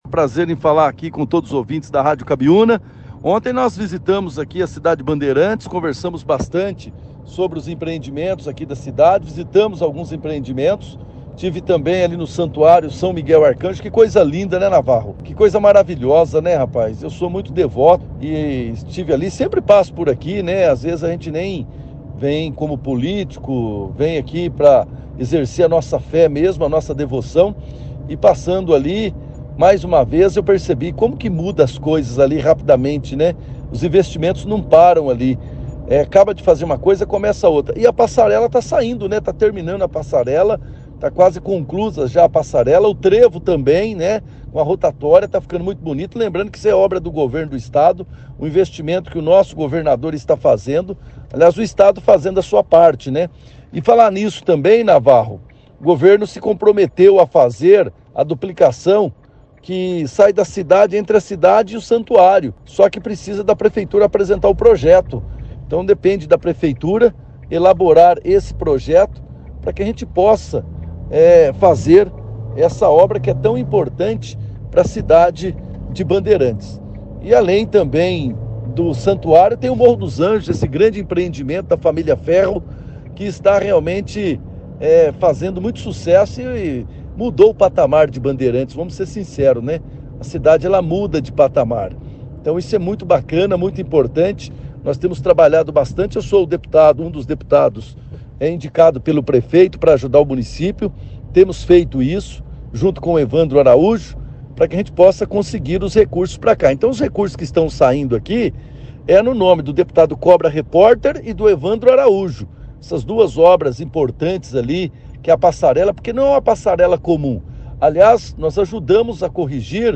A visita do deputado Cobra Reporter foi destaque na 2ª edição do jornal Operação Cidade, nesta sexta-feira, 07/07, onde ele compartilhou sua experiência e impressões sobre a visita realizada. Durante a entrevista, o deputado abordou diversos tópicos de relevância para a região, ressaltando a importância do Santuário de São Miguel Arcanjo e destacando o grande investimento do Morro dos Anjos Resort.